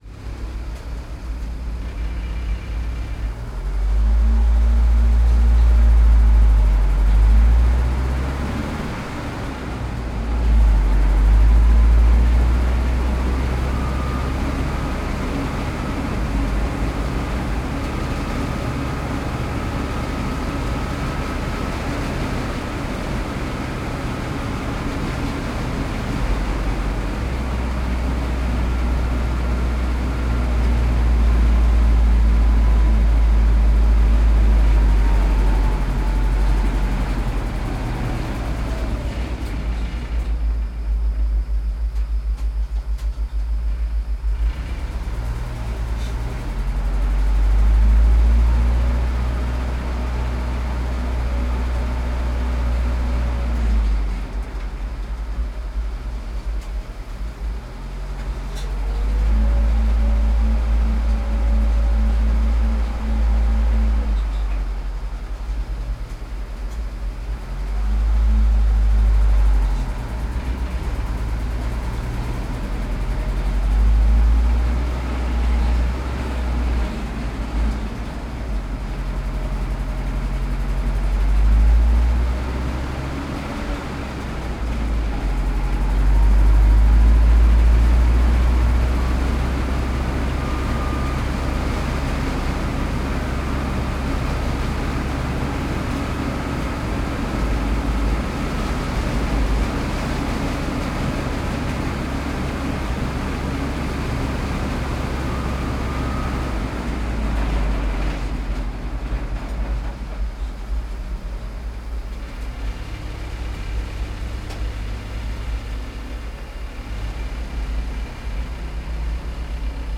Pole Position - Sherman M4A3 Medium Tank